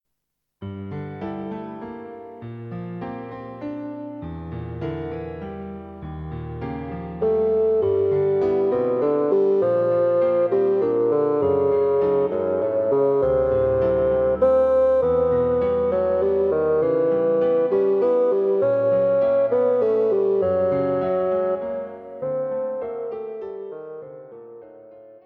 Bassoon Solo and Piano More suited for the better player.